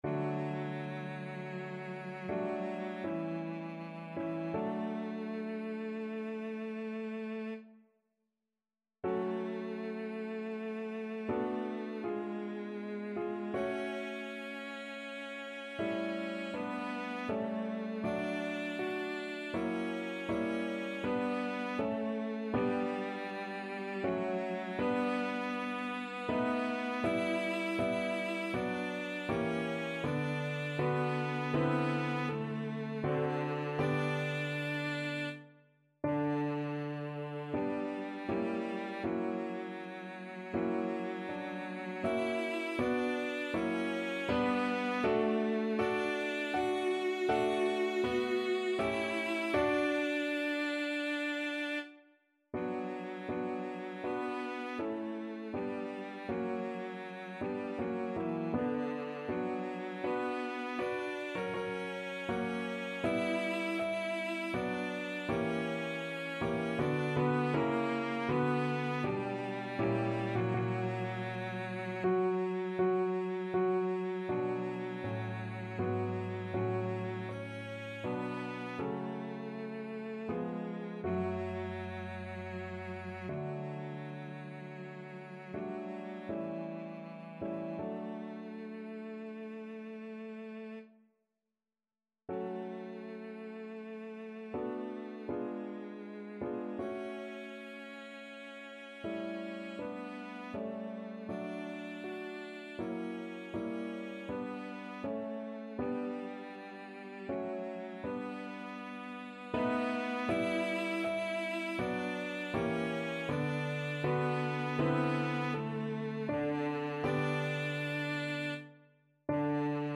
3/4 (View more 3/4 Music)
~ = 80 Andante ma non lento
Classical (View more Classical Cello Music)